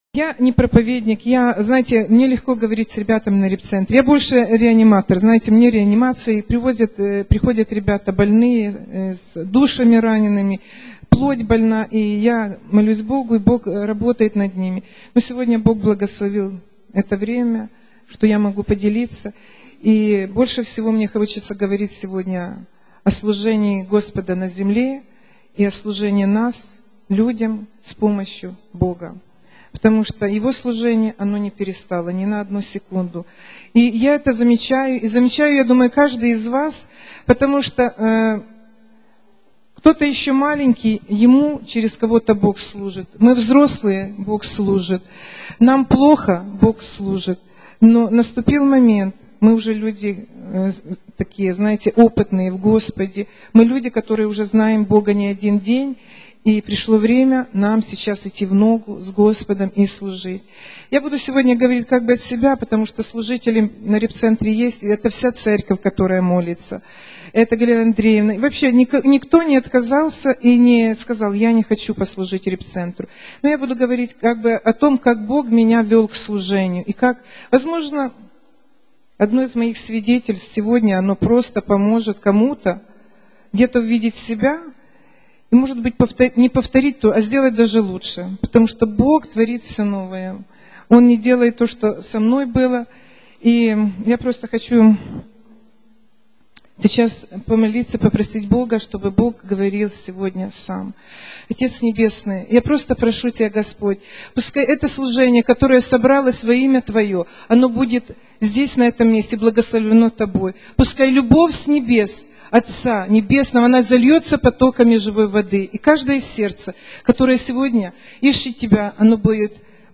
Актуальна проповідь